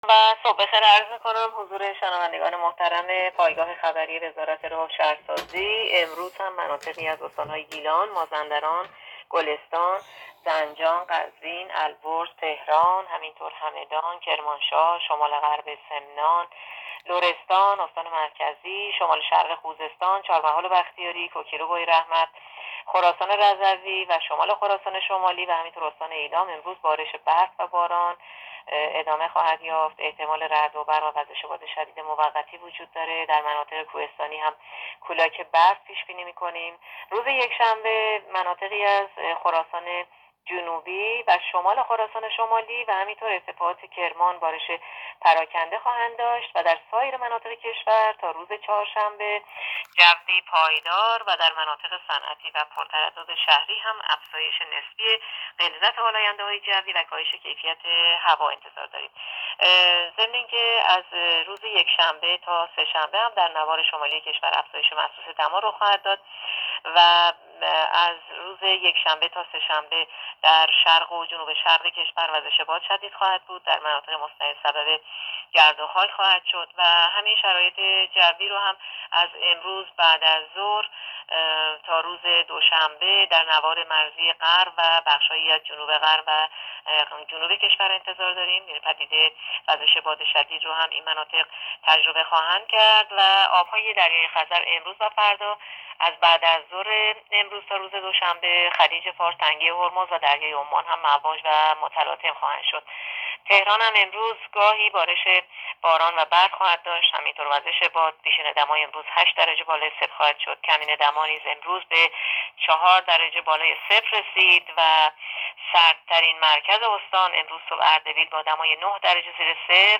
گزارش رادیو اینترنتی از آخرین وضعیت آب و هوای ۱۳ دی؛